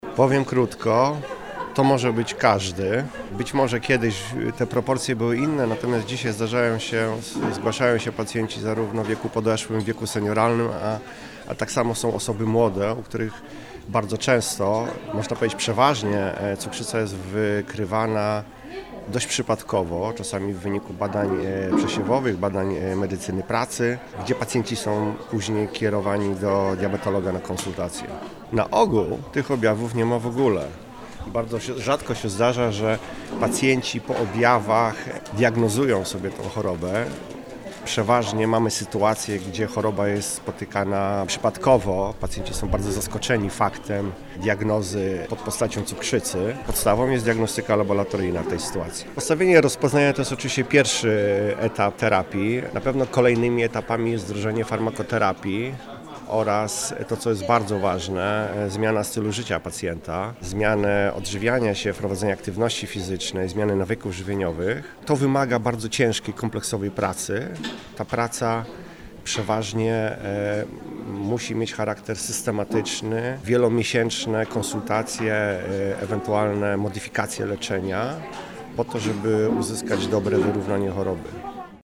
W rozmowie z Radiem Rodzina wyjaśnia, na co należy zwrócić uwagę przy diagnozowaniu cukrzycy.